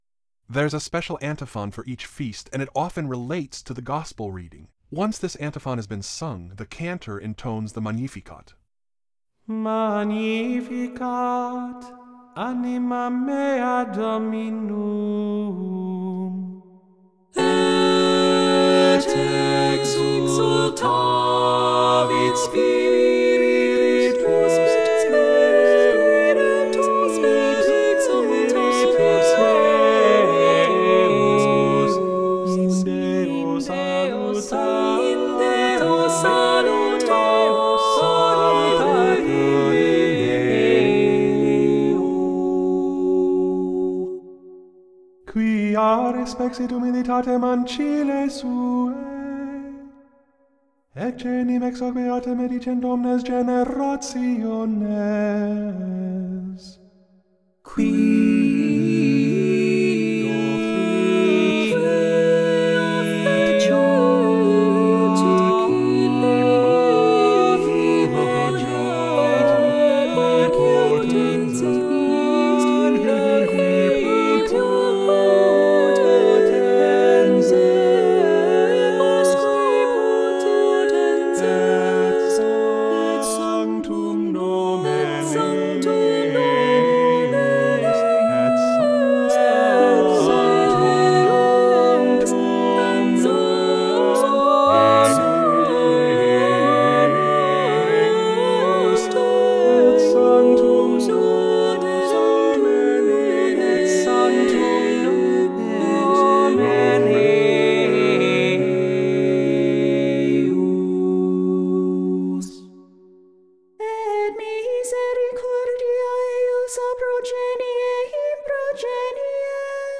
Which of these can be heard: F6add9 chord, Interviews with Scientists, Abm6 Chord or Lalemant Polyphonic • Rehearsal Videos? Lalemant Polyphonic • Rehearsal Videos